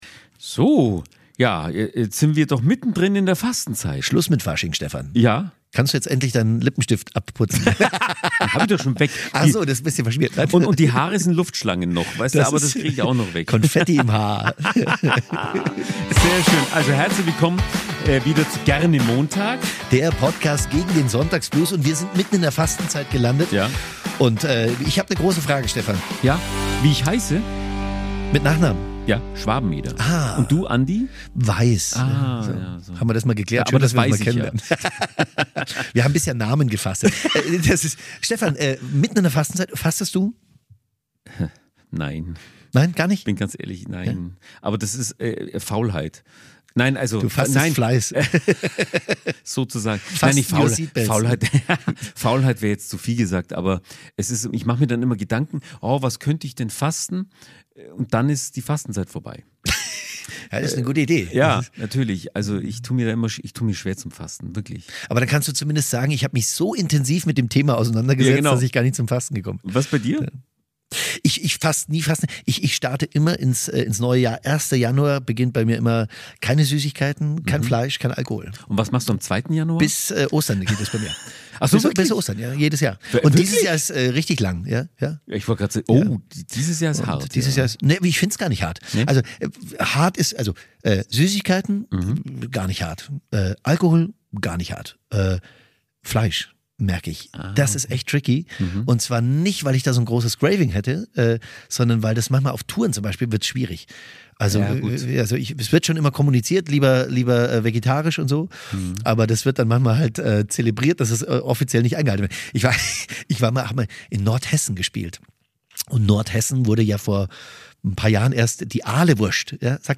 Im Gespräch wollen die beiden herausfinden, wie das Fasten leichter fällt, und finden dabei einen neuen Zugang.